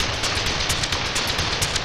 RI_DelayStack_130-04.wav